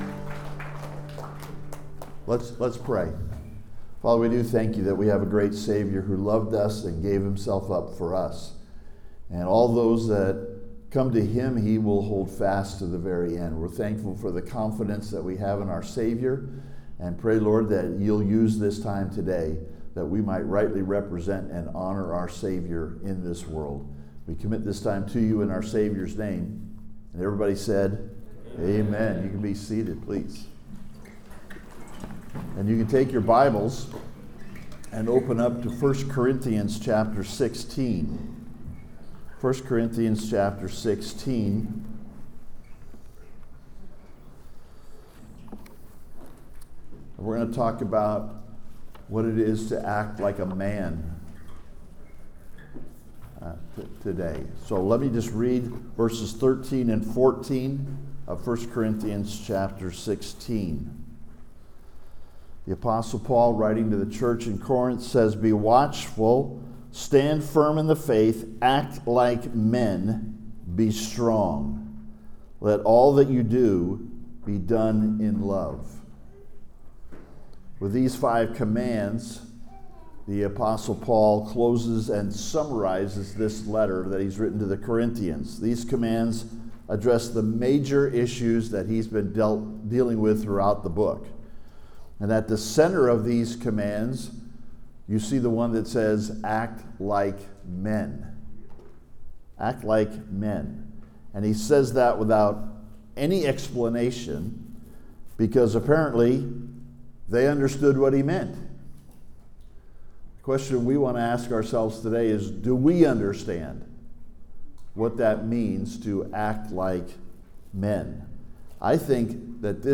Time to Act Like Men (Sermon) - Compass Bible Church Long Beach